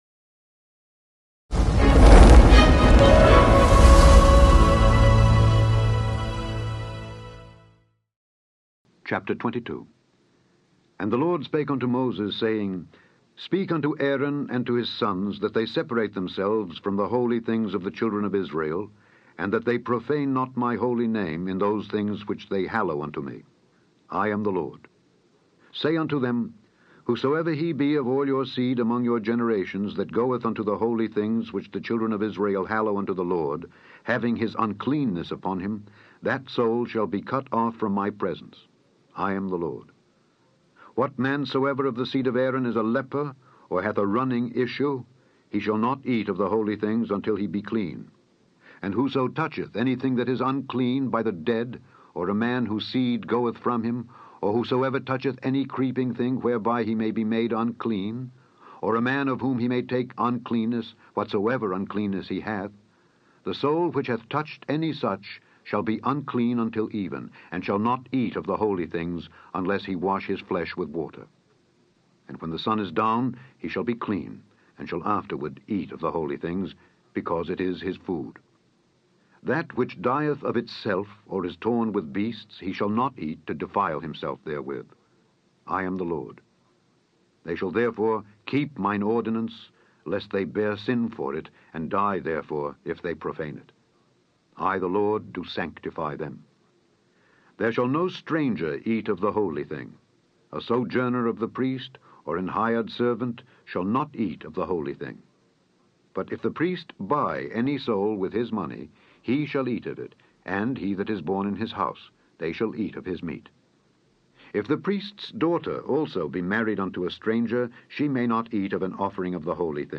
Daily Bible Reading: Leviticus 22-24
Click on the podcast to hear Alexander Scourby read Leviticus 22-24.